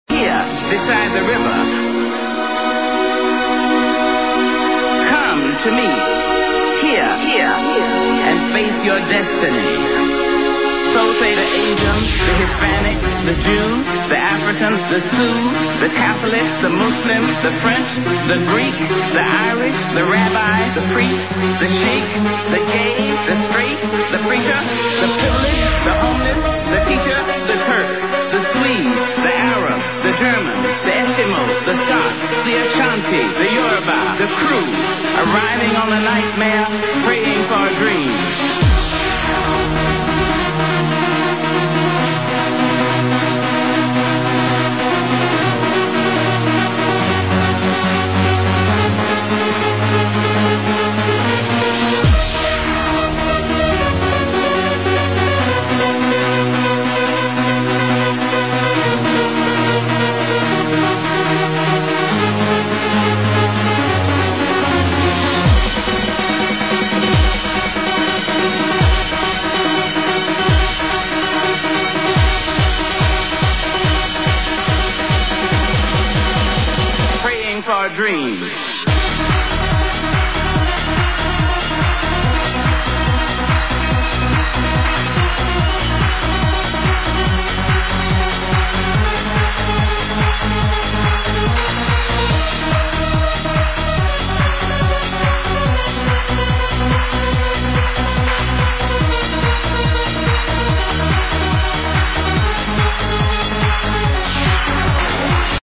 A trance tune